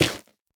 Minecraft Version Minecraft Version latest Latest Release | Latest Snapshot latest / assets / minecraft / sounds / block / nylium / break3.ogg Compare With Compare With Latest Release | Latest Snapshot